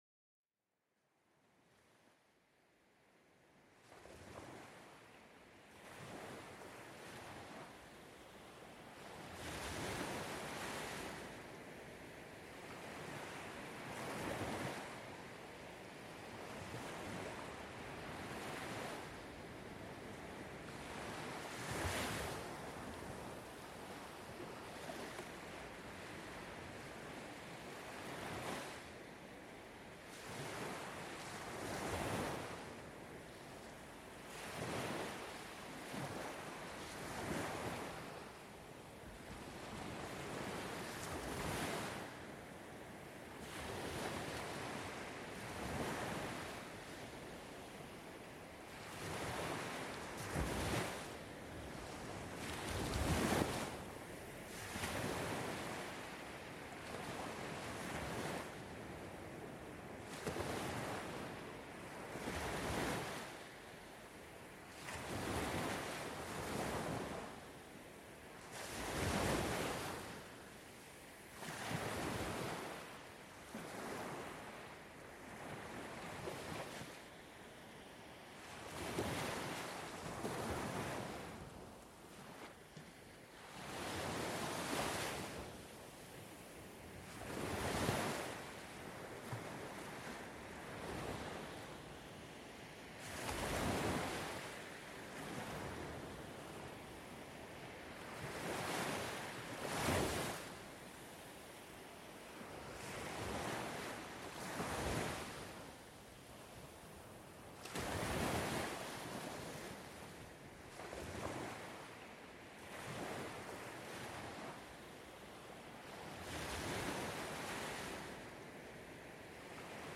Vagues de l'océan: Relaxation et Sommeil Profond
Laissez-vous bercer par le doux son des vagues de l'océan dans cet épisode apaisant. Ressentez la tranquillité et la sérénité en vous immergeant dans le bruit des vagues s'écrasant doucement sur la plage.